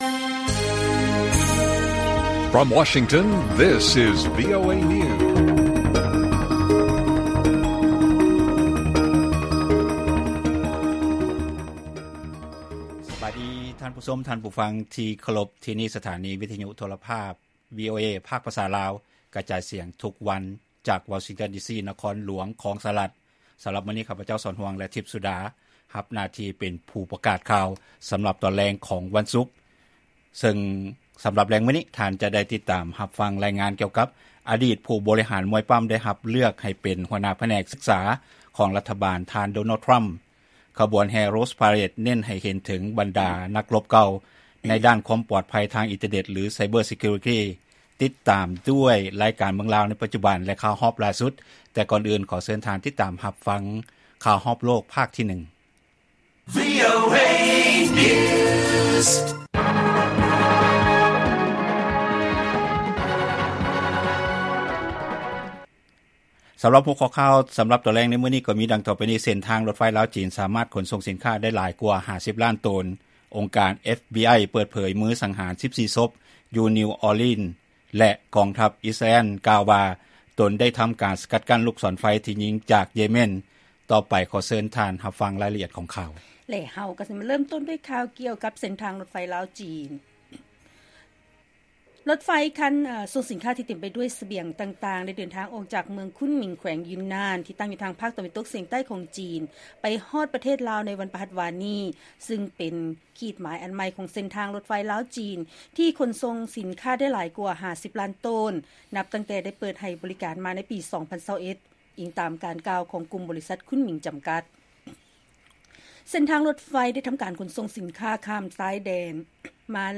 ລາຍການກະຈາຍສຽງຂອງວີໂອເອລາວ: ເສັ້ນທາງລົດໄຟ ລາວ-ຈີນ ສາມາດຂົນສົ່ງສິນຄ້າໄດ້ຫຼາຍກວ່າ 50 ລ້ານໂຕນ